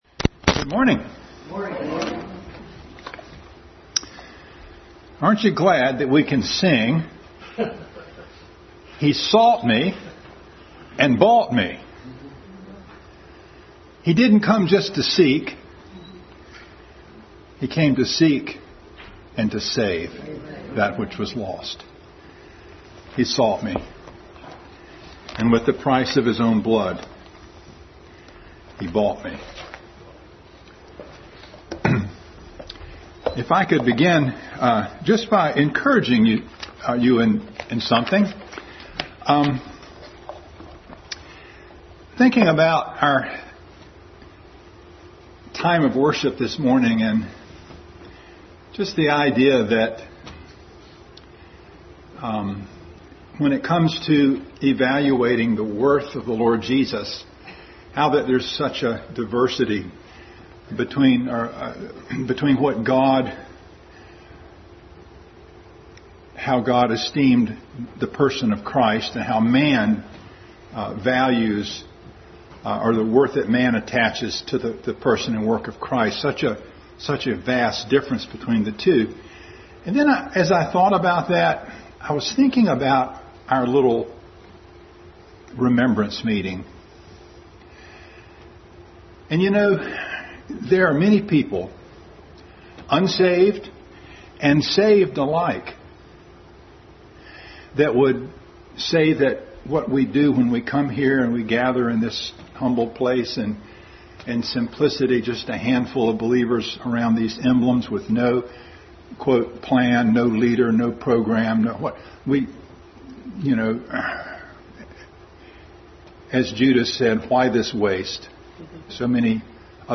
Adult Sunday School Class beginning study of Galatians.
John 18:37 Service Type: Sunday School Adult Sunday School Class beginning study of Galatians.